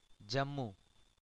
The Jammu division (/ˈæm, ˈʌm-/